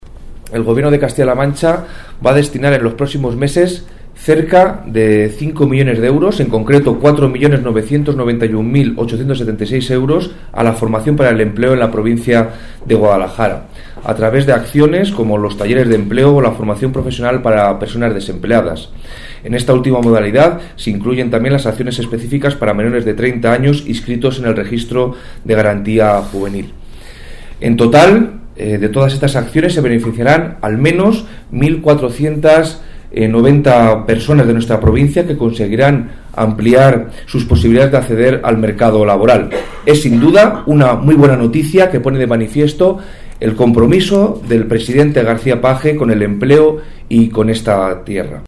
El delegado de la Junta en Guadalajara habla de la inversión en el total de acciones de formación para el empleo que llevará a cabo el Gobierno regional en la provincia de Guadalajara en 2017